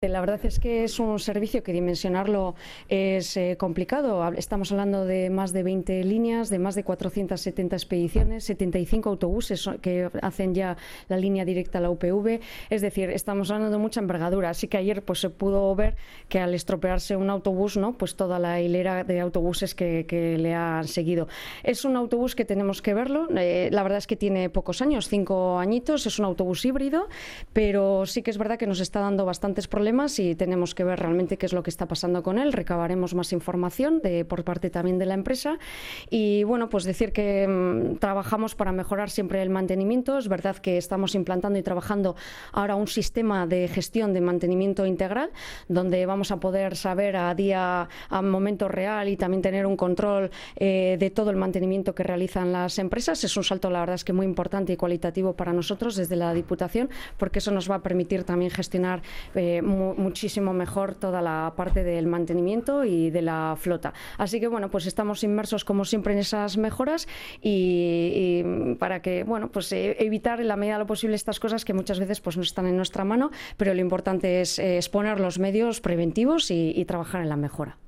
Sonia Pérez, diputada de Transportes, Movilidad y Turismo de Bizkaia en los estudios de ONDA VASCA